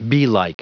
Prononciation du mot beelike en anglais (fichier audio)
Prononciation du mot : beelike